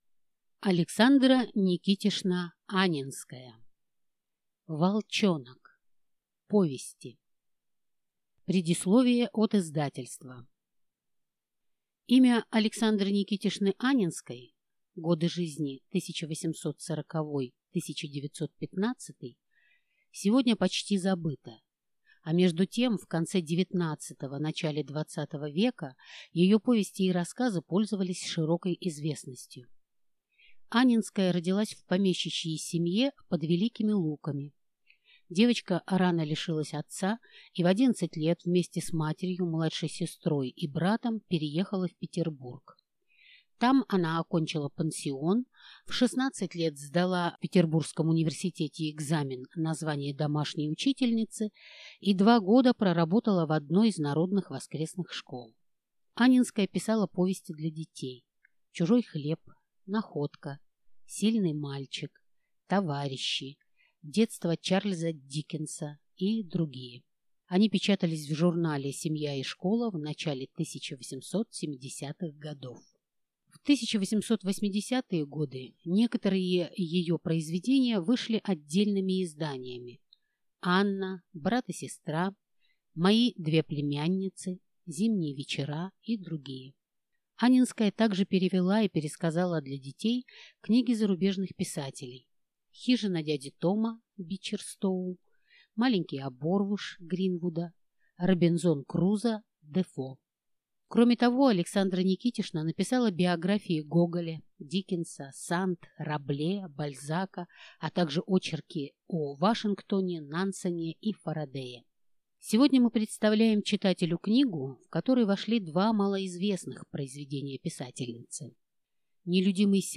Аудиокнига Волчонок (сборник) | Библиотека аудиокниг